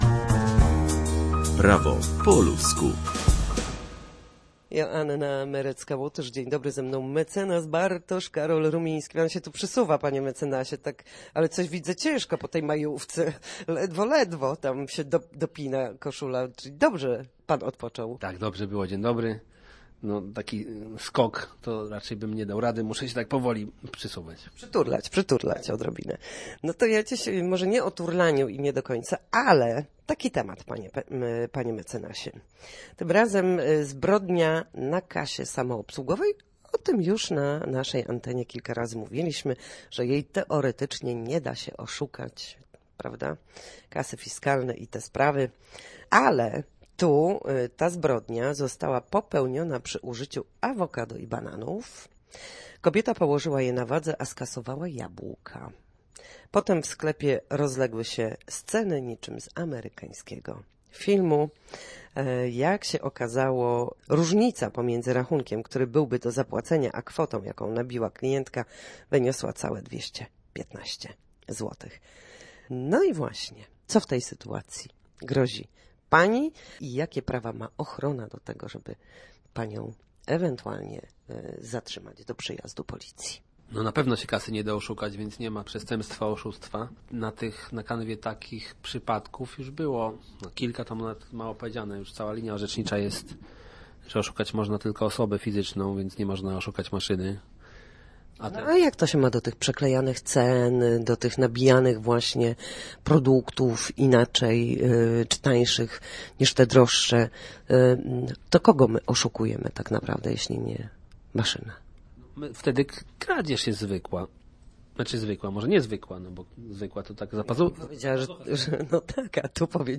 W każdy wtorek po godzinie 13 na antenie Studia Słupsk przybliżamy państwu meandry prawa.
Nasi goście, prawnicy, odpowiadają na jedno pytanie dotyczące zachowania w sądzie czy podstawowych zagadnień prawniczych.